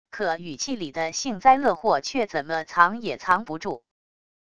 可语气里的幸灾乐祸却怎么藏也藏不住wav音频生成系统WAV Audio Player